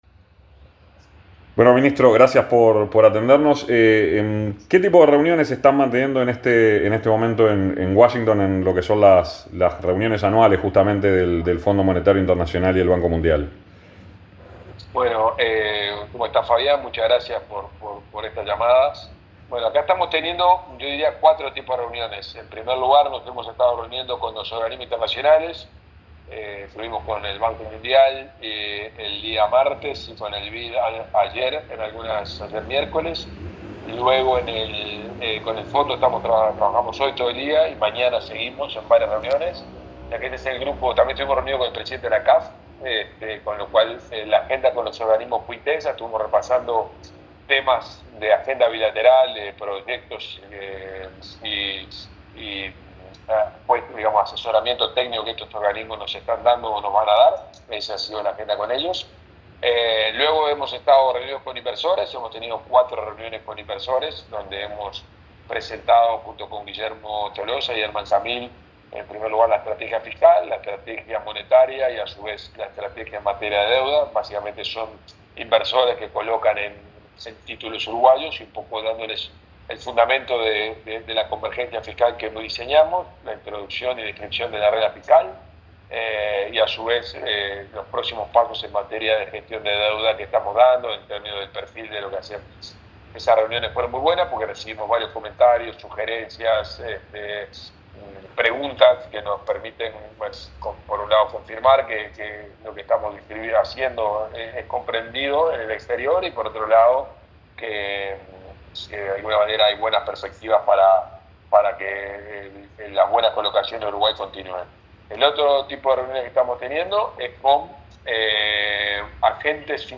Oddone en entrevista con 970 Universal habló sobre presupuesto, secreto bancario y reuniones con inversores en EEUU - 970 Universal
El ministro de Economía y Finanzas, Gabriel Oddone habló en entrevista con Punto de Encuentro desde Washington DC, donde asiste a las reuniones anuales del Fondo Monetario Internacional y el Banco Mundial y se refirió a una emisión de deuda uruguaya y el interés de empresas por Uruguay.